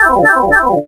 Sound effect of Pipe Travel in Yoshi's Island DS.
YIDS_Pipe_Travel.oga